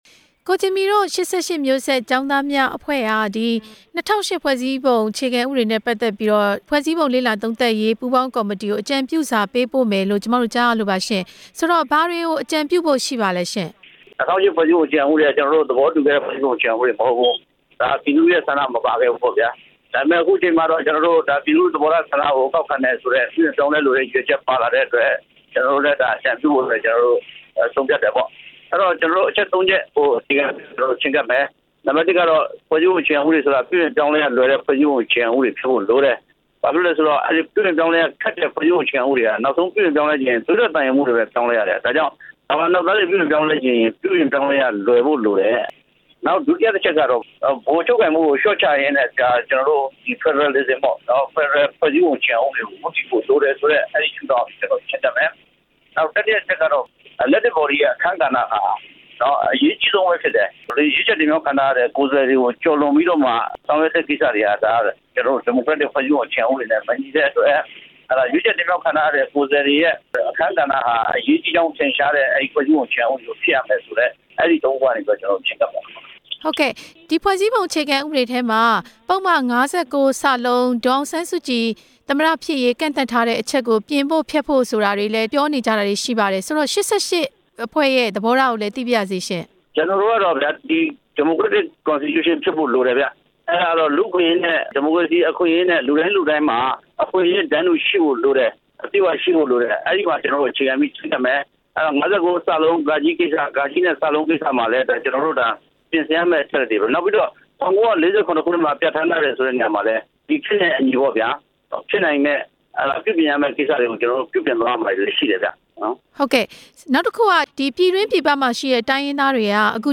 ကိုဂျင်မီနဲ့ မေးမြန်းချက်